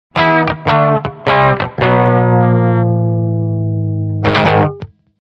pizza tower time up Meme Sound Effect
Category: Games Soundboard